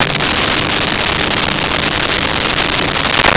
Pipe Groan 02
Pipe Groan 02.wav